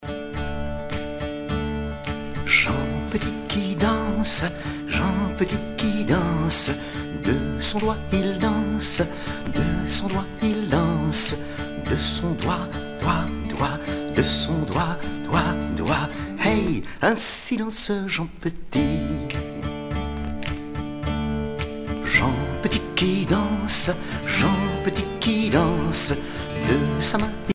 Couplets à danser